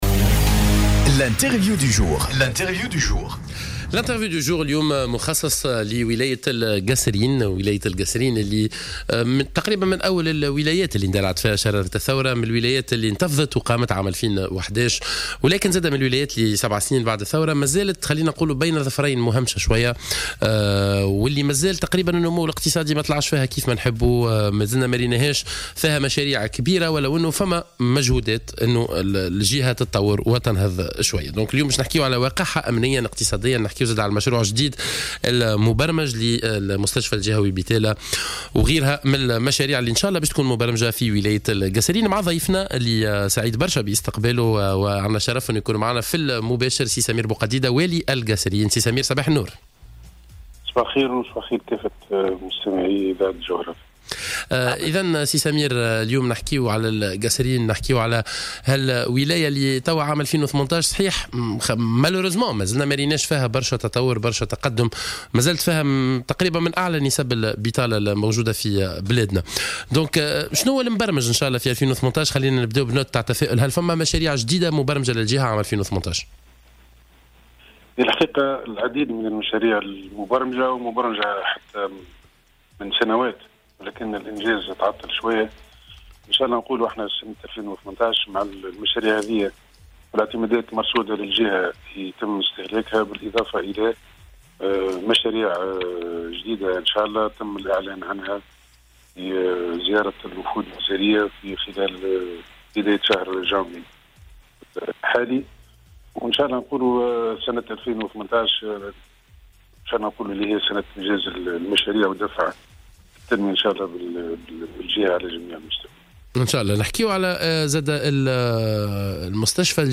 رجّح والي القصرين، سمير بوقديدة، في تصريح لـ "الجوهرة أف أم" في برنامج "صباح الورد" اليوم الخميس، الإعلان عن طلب عروض لانجاز مستشفى جهوي في تالة في شهر جوان أو جويلية المقبلين على اقصى تقدير.